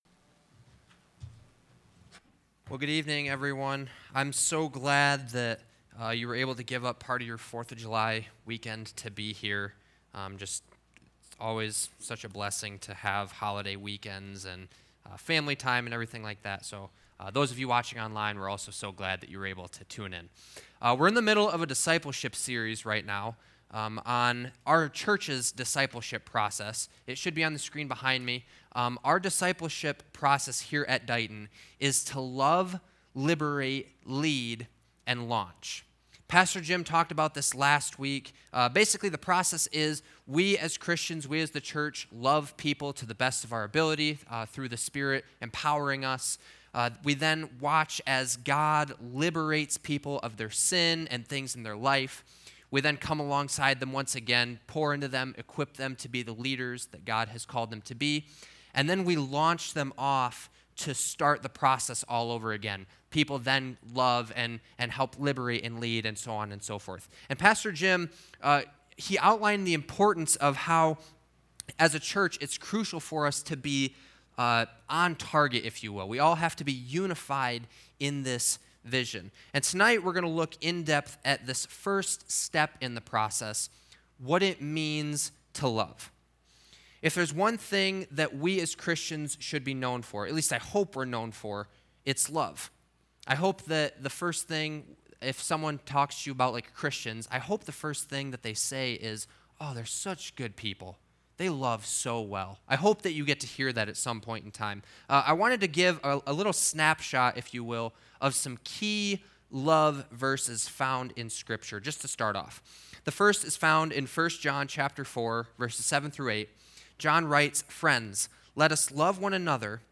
Matthew 5:21-22 Service Type: Sunday Morning